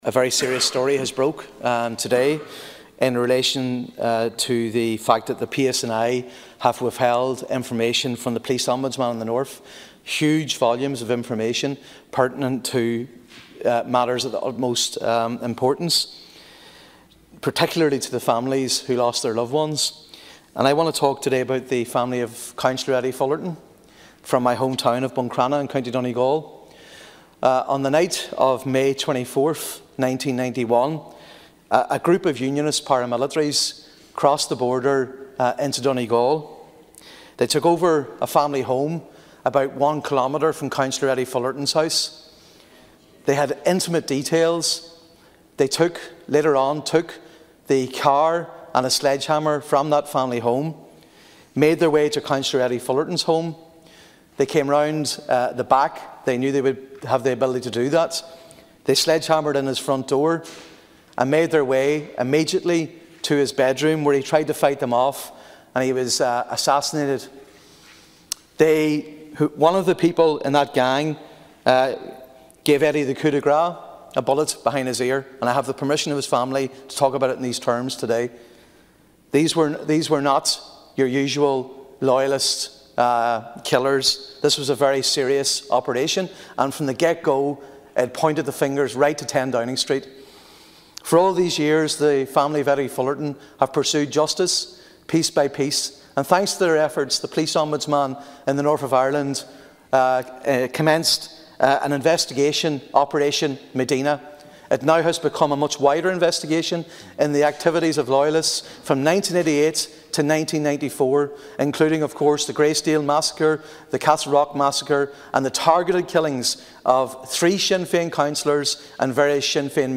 Senator Mac Lochlainn told the Seanad this is a very serious development: